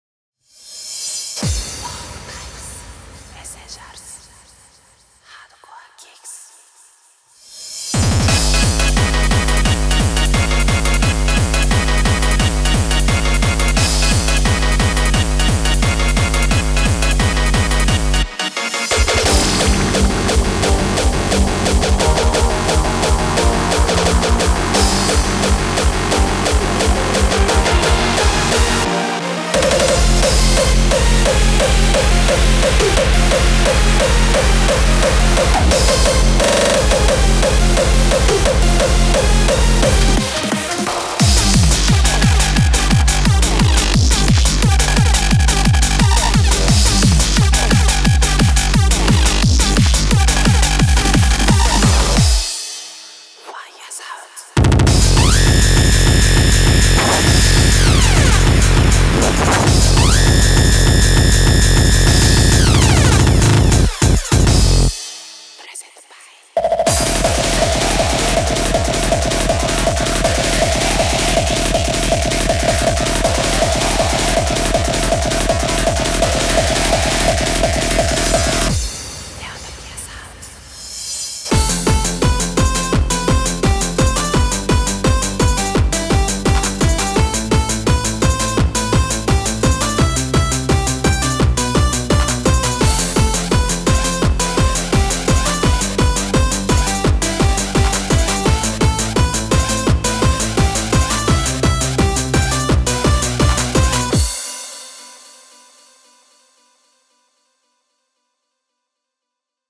– 65 kick one-shot samples (wav, 44.1 kHz/16-bit)
– Demo song (mp3)
Demo